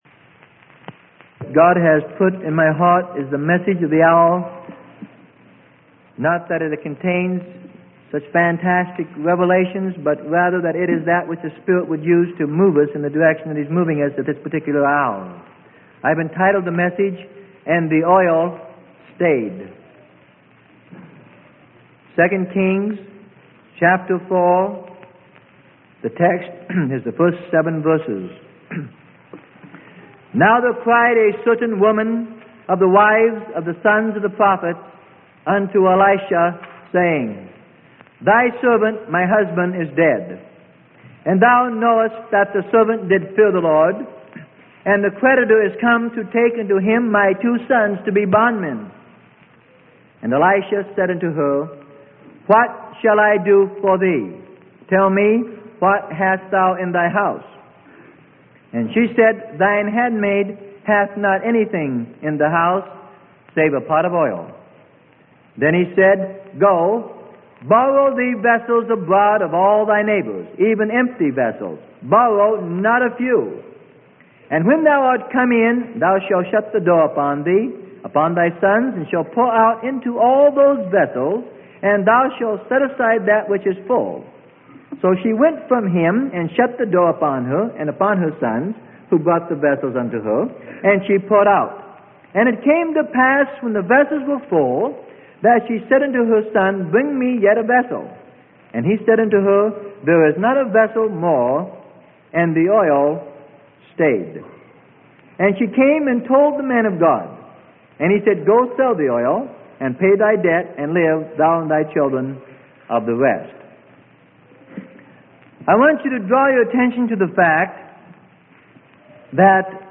Sermon: And the Oil Stayed - Freely Given Online Library